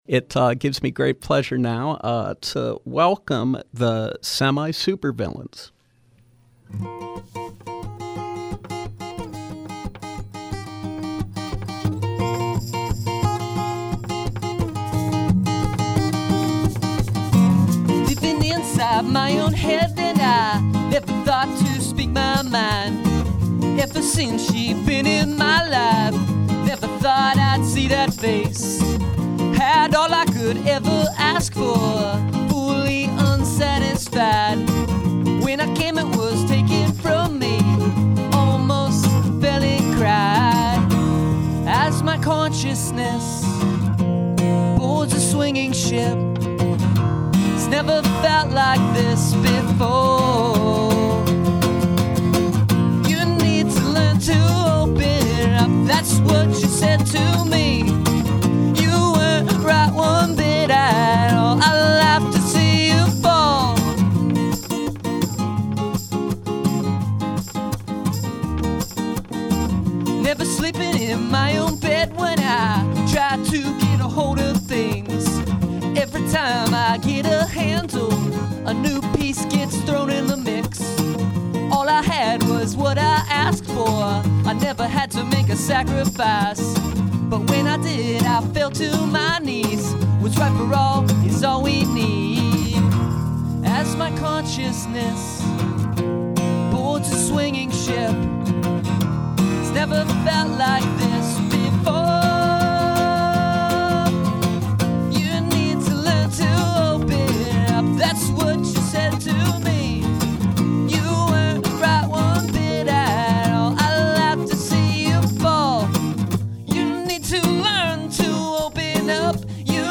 Live acoustic set
local garage rock band